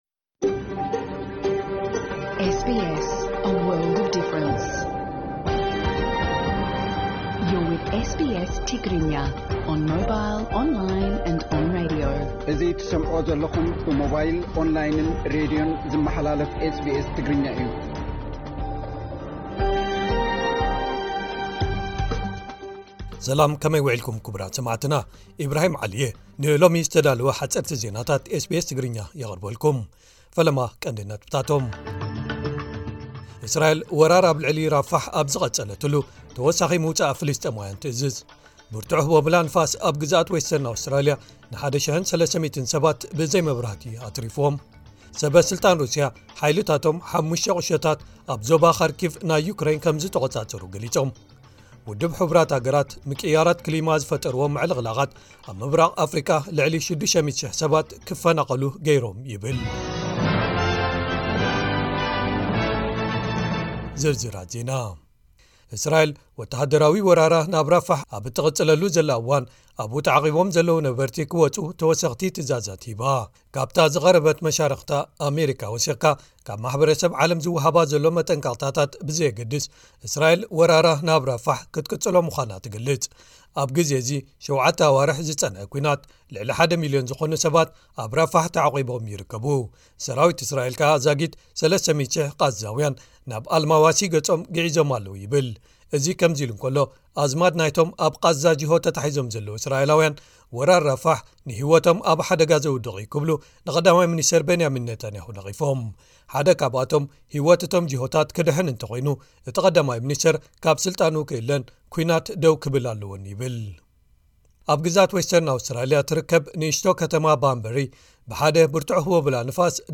ሓጸርቲ ዜናታት ኤስ ቢ ኤስ ትግርኛ (12 ግንቦት 2024)